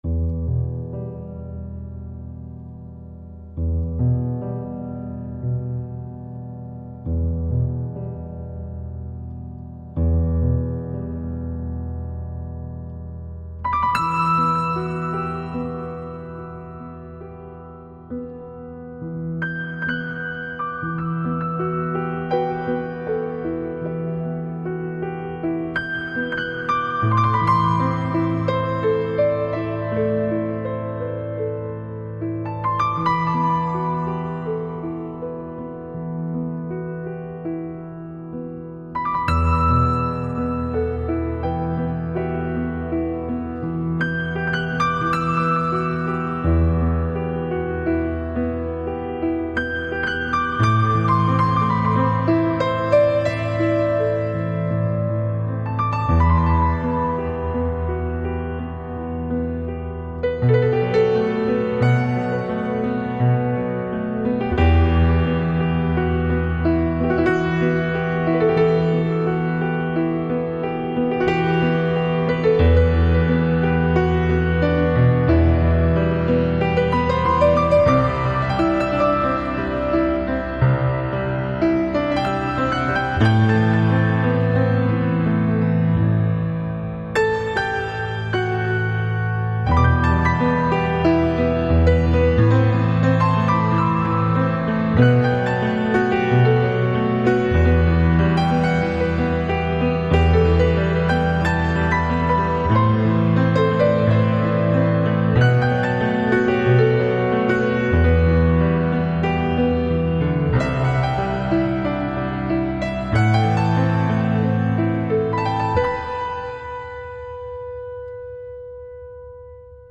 Жанр: Piano, Instrumental, New Age